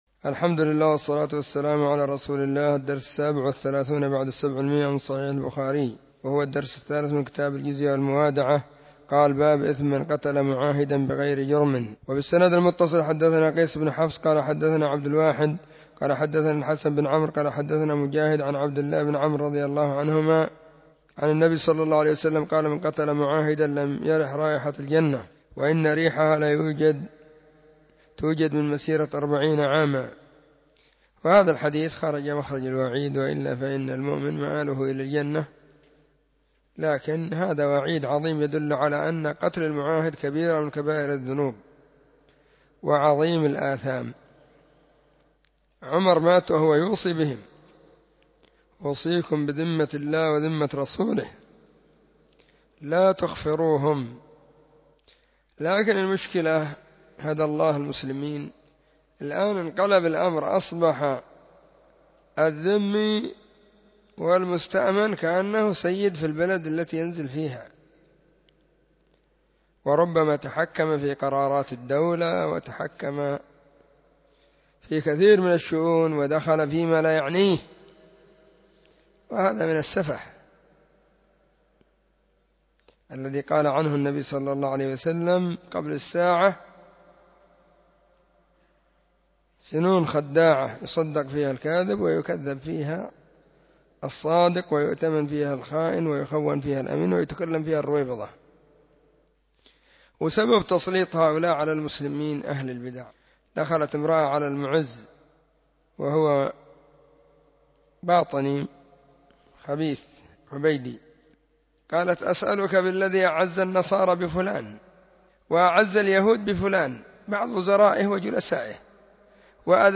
🕐 [بين مغرب وعشاء – الدرس الثاني]